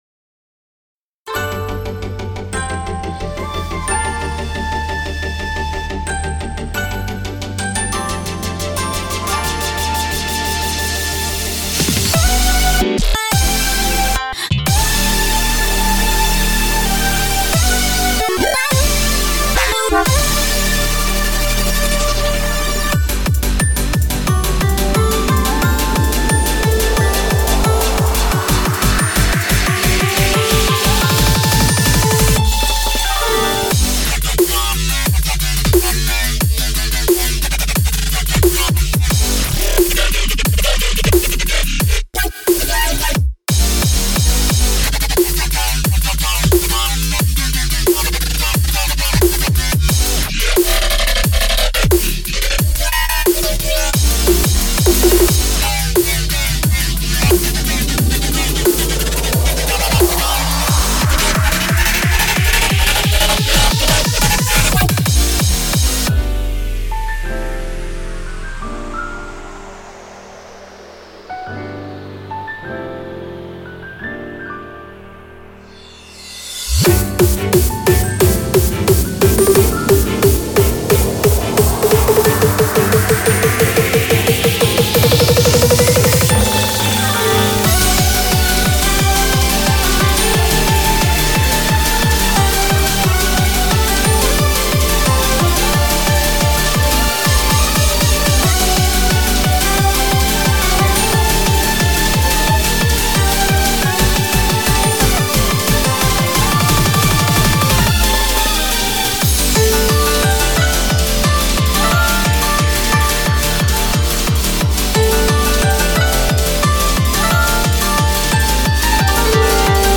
BPM89-178